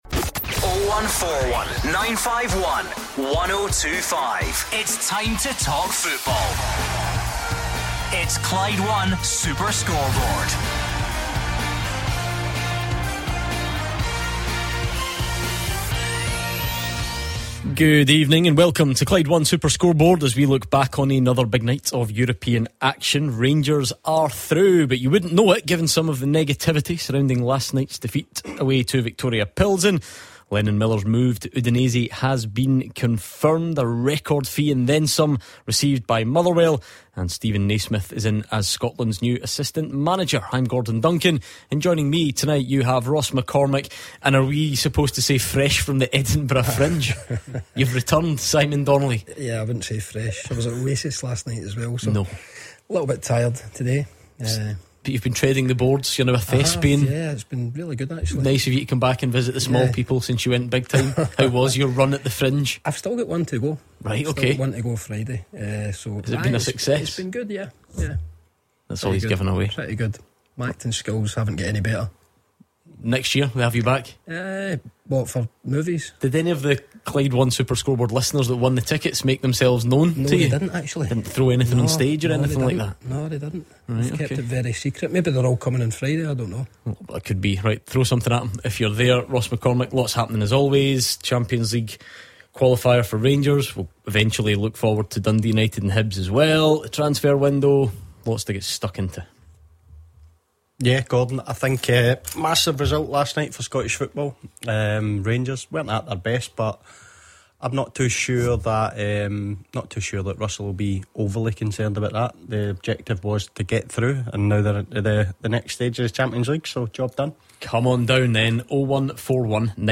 they take Rangers fans' calls on last night's progression to the Champions League play-off after a 4-2 aggregate win over Viktoria Plzen